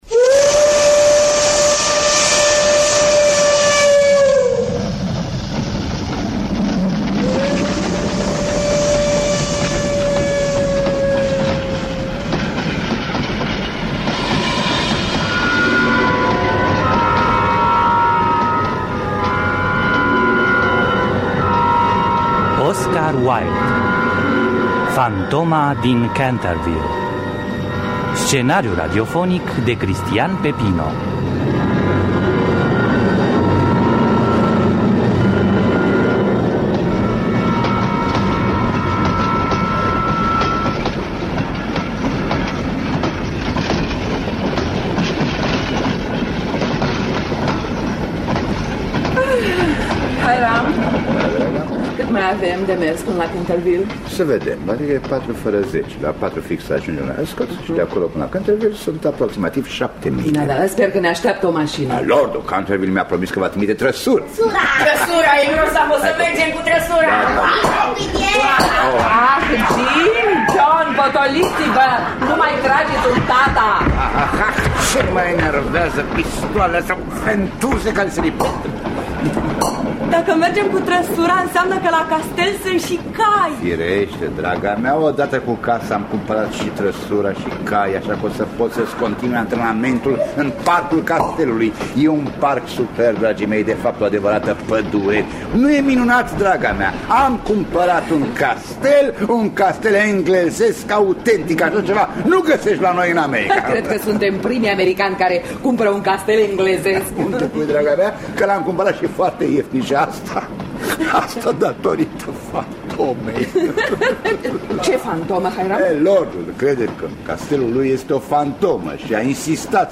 Fantoma din Canterville de Oscar Wilde – Teatru Radiofonic Online